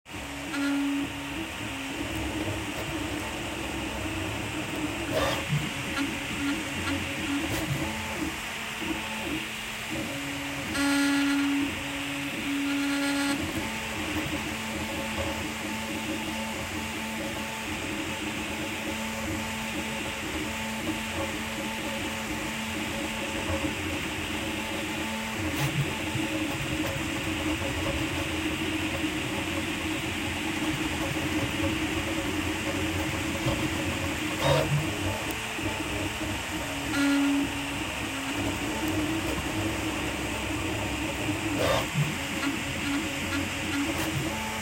Vibration/Squeaking noise when moving on the X axis during p...
55ea04ef-mk4-x-axis-resonance-sound.m4a
you hear the resonance at about the 10 sec mark.
after further observation, it appears that the MK4 only makes this sound when the head is moving purely (or nearly so) in the x direction and only at certain speeds.